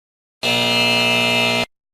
Descargar-Efecto-de-Sonido-Falla-Error-en-HD-Sin-Copyright-.mp3